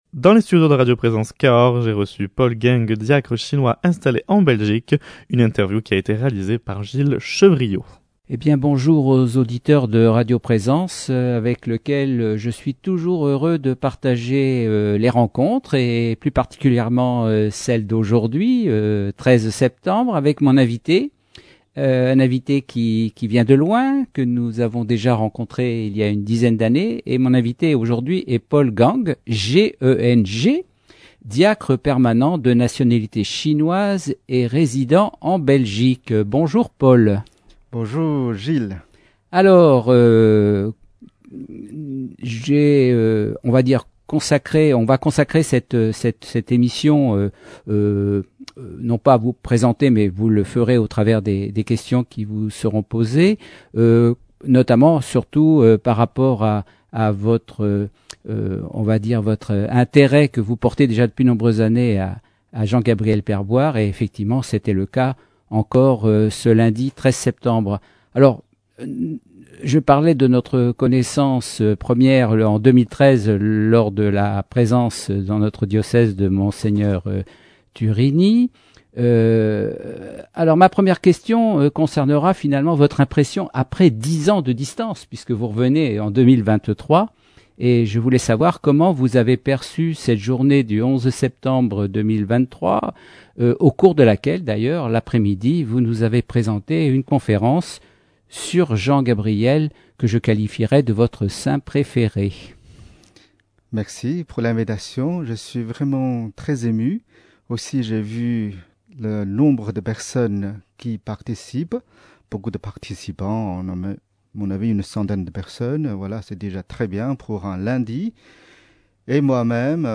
Interview
Dans les studios de radio présence Cahors